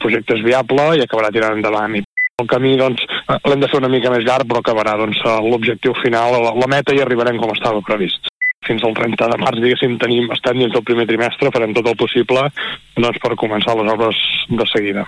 L’alcalde Buch hi ha tret ferro. Es mostra optimista en la conclusió del procés negociat que s’ha iniciat amb entitats bancàries per obtenir el préstec de 5,6 milions d’euros que cobreixin la inversió.